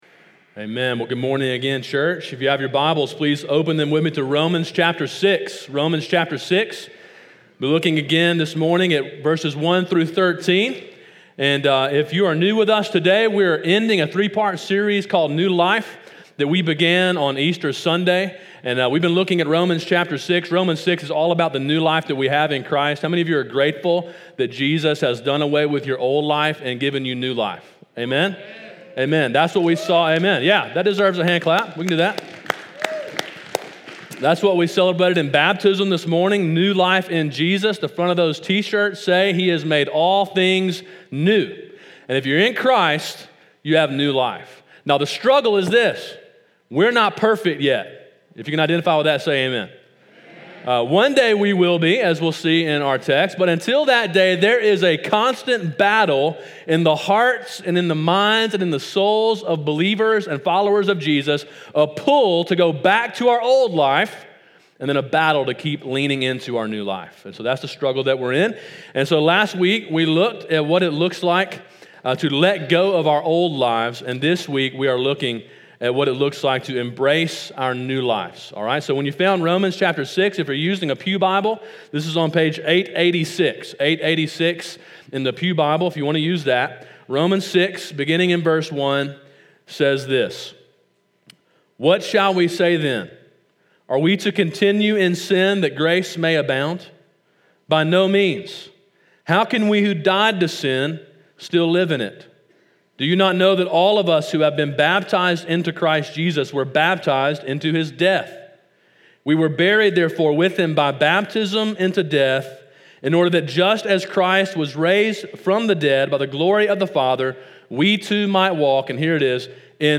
Sermon: “Embracing Your New Life” (Romans 6:1-13) – Calvary Baptist Church
sermon5.05.19.mp3